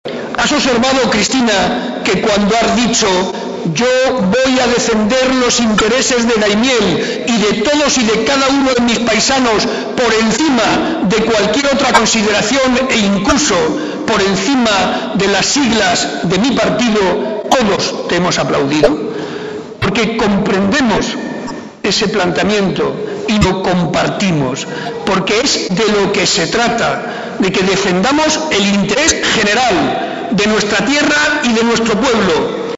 Barreda hacía estas manifestaciones durante la presentación de Cristina Maestre como candidata a la Alcaldía de Daimiel, en un discurso en el que enumeró las prioridades del Gobierno regional, que pasan por las infraestructuras de comunicaciones e hidráulicas, pero sobre todo por las políticas educativas, sanitarias y sociales.
Cortes de audio de la rueda de prensa